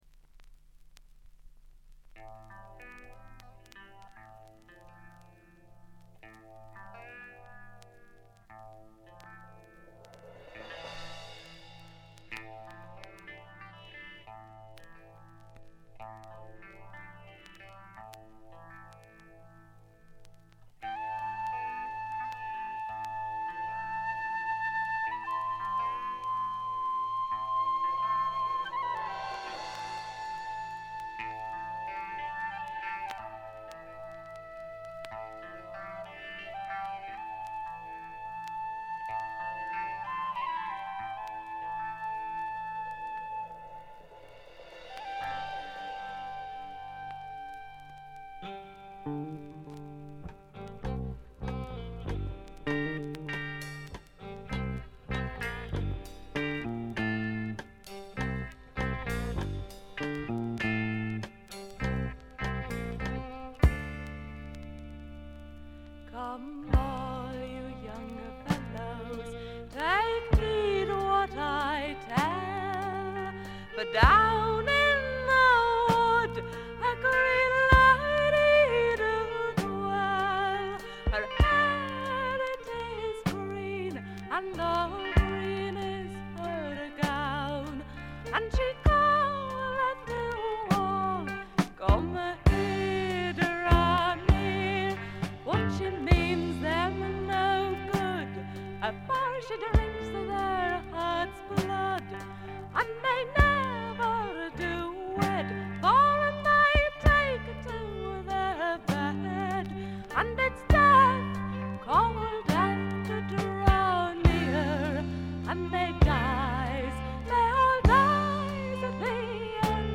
静音部で軽微なチリプチが聴かれますが鑑賞に影響するようなノイズはありません。
ドラムとベースがびしばし決まるウルトラグレートなフォーク・ロックです。
試聴曲は現品からの取り込み音源です。